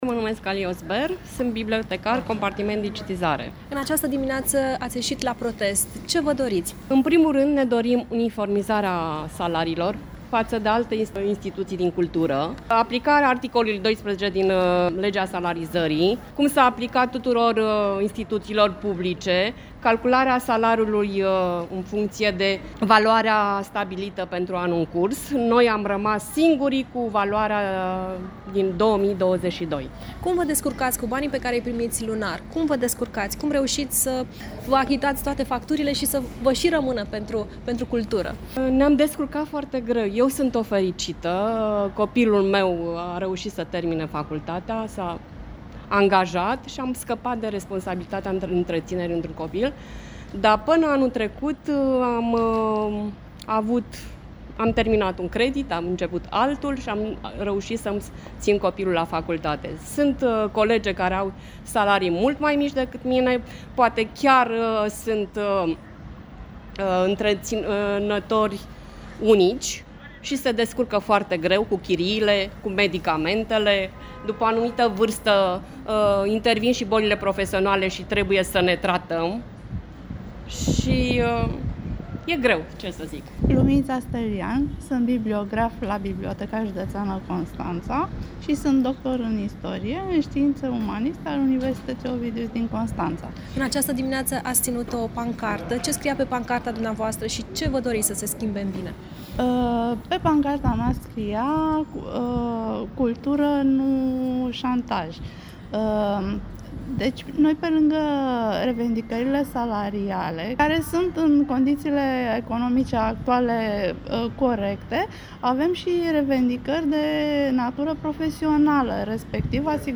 AUDIO | De Ziua Culturii Naționale, bibliotecarii constănțeni și-au strigat în stradă nemulțumirile
La Biblioteca Județeană Constanța, bibliotecarii au ieșit cu pancarte în fața instituției.
PROTEST-BIBLIOTECA.mp3